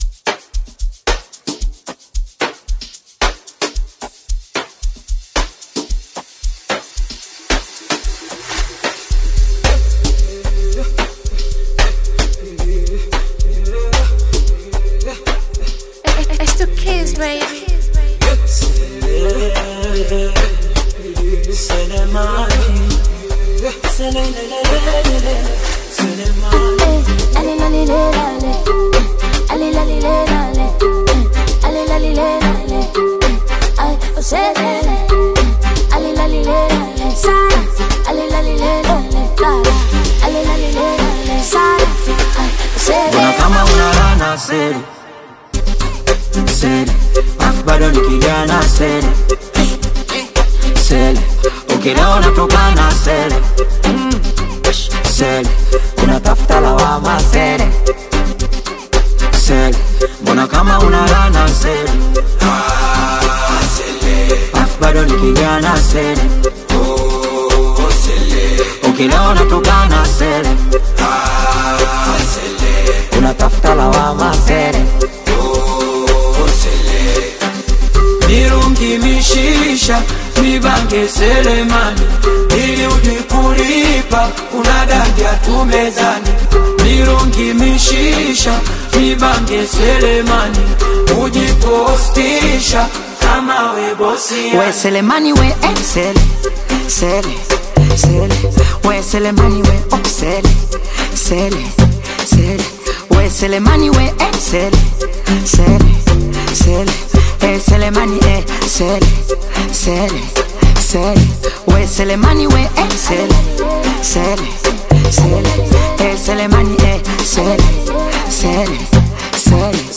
comes through with a brand new amapiano influenced single